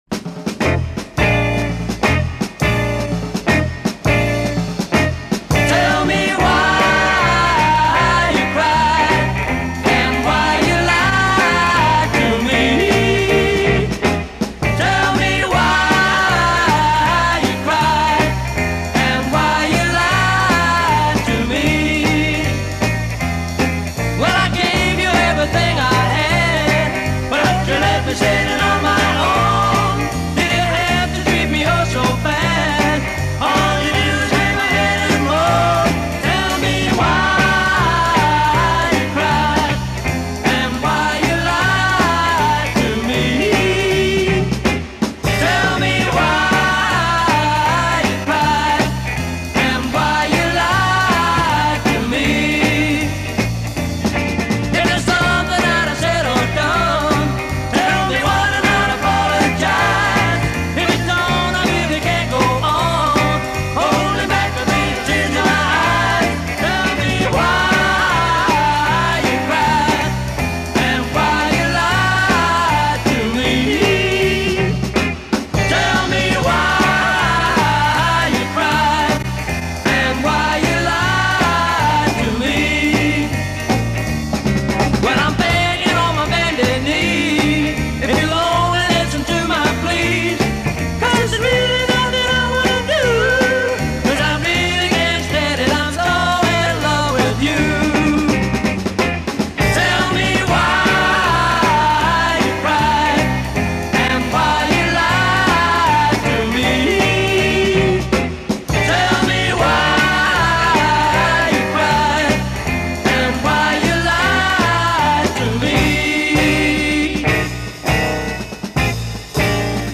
Очень близко к оригиналу.